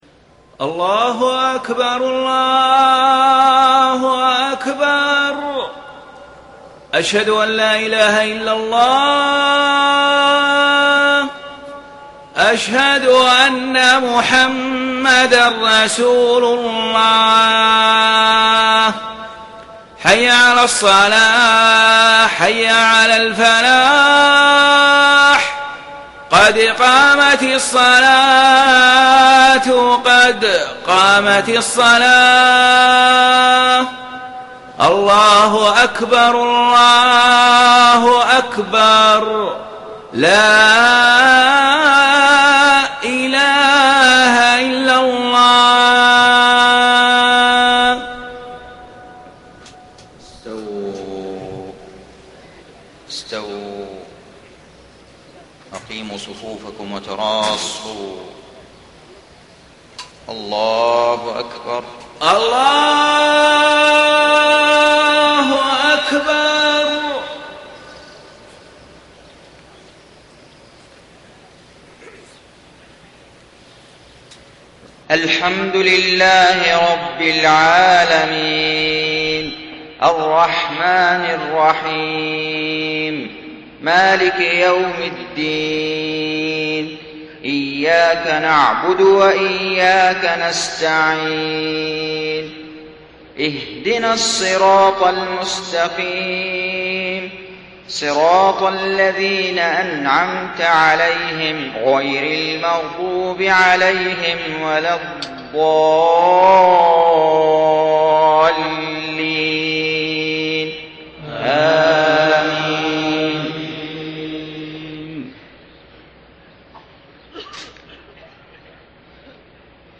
صلاة الفجر 21 ذو القعدة 1432هـ سورتي عبس و الانفطار > 1432 🕋 > الفروض - تلاوات الحرمين